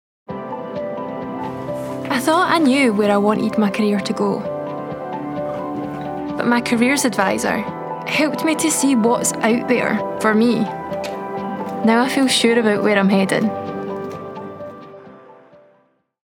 Native Scottish.
• Female
• Edinburgh
• Glasgow
• Scots